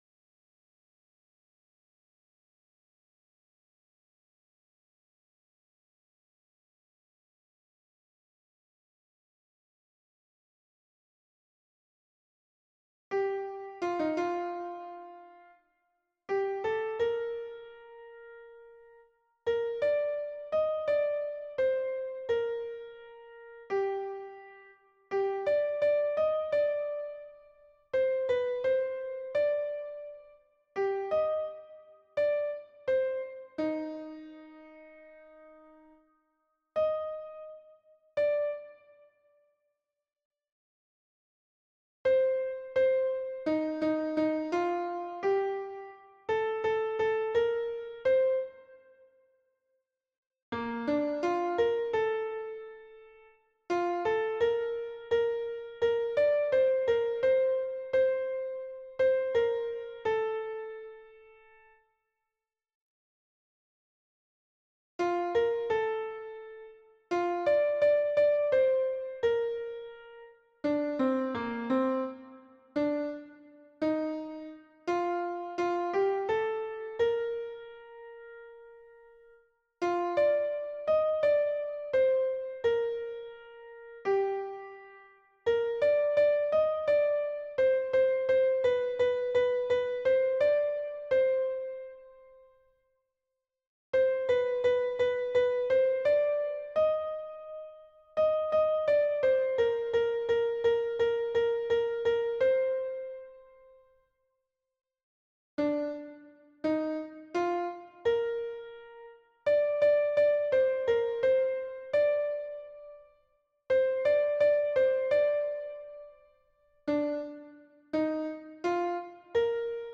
MP3 version piano - vitesse réduite pour apprentissage
Mezzo-soprano